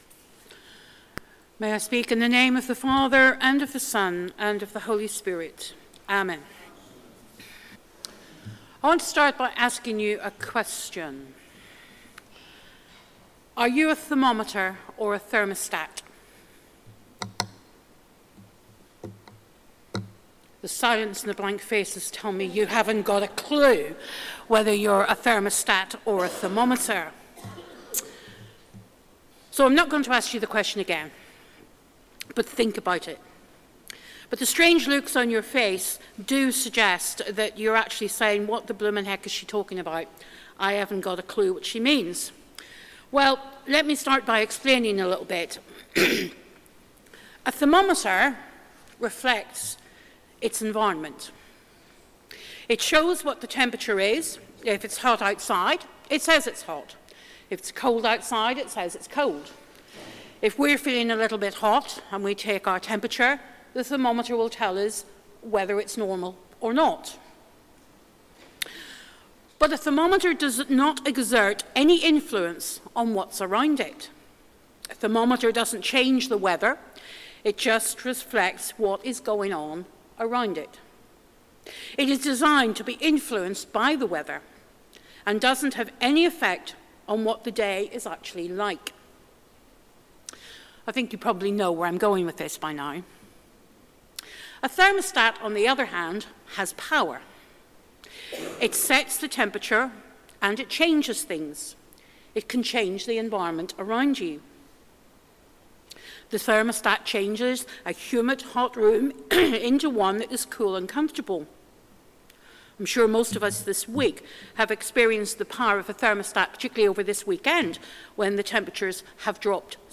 Sermon: Are you a thermometer or a thermostat?